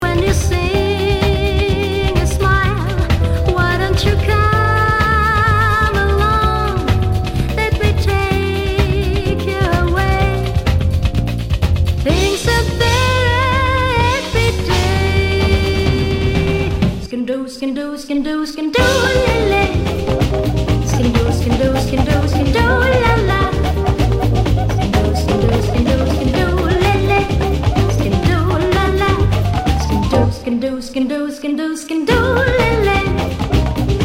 日本を代表する人気女性ジャズヴォーカリストの代表作を集めて'01にリリースされた人気シングル。'
Tag       OTHER ROCK/POPS/AOR